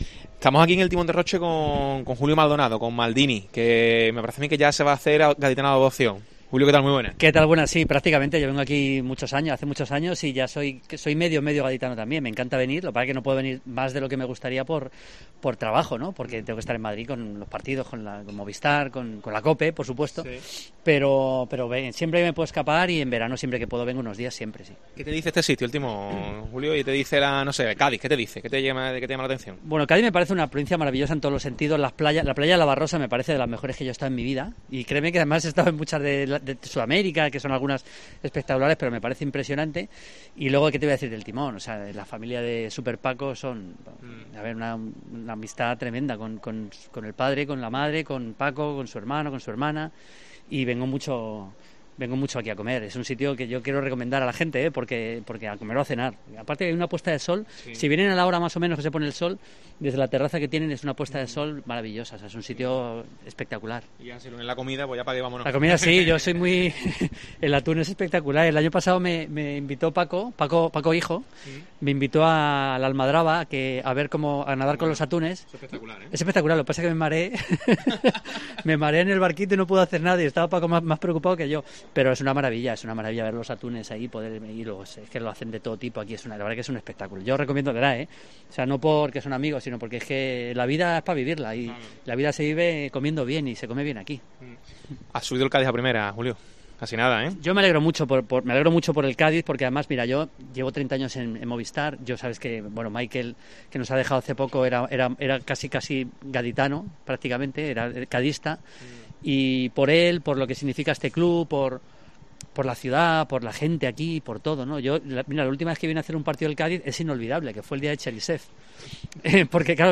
El periodista deportivo analiza el ascenso del Cádiz CF en los micrófonos de COPE Cádiz